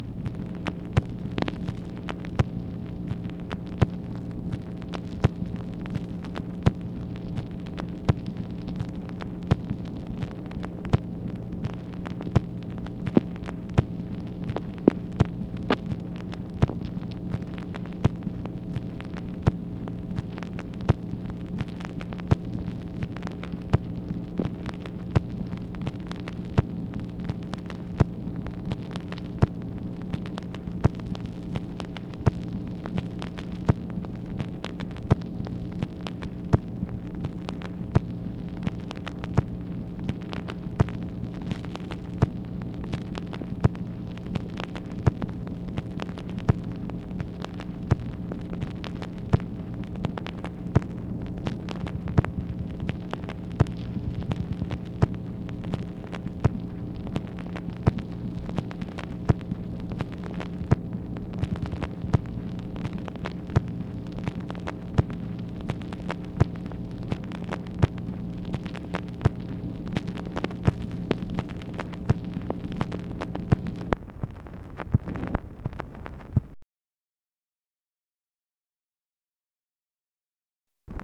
MACHINE NOISE, August 23, 1965
Secret White House Tapes | Lyndon B. Johnson Presidency